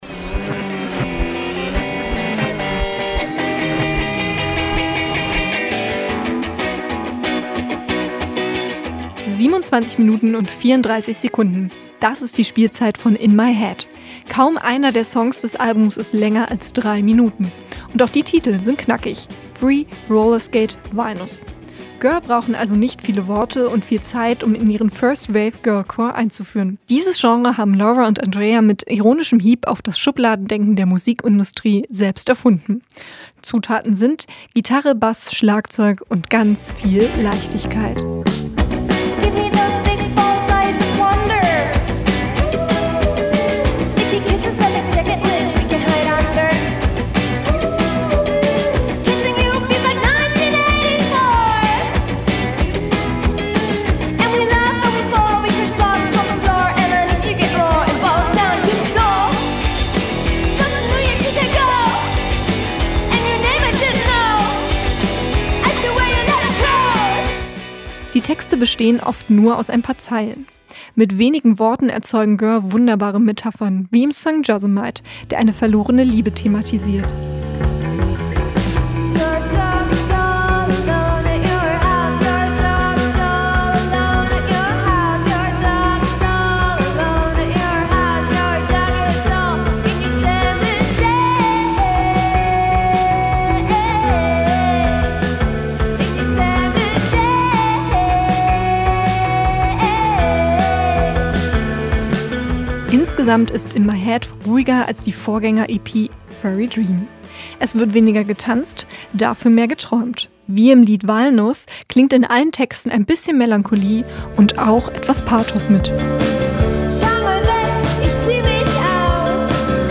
Eine Genre-Mix aus Garage, Surf und Punk-ELementen.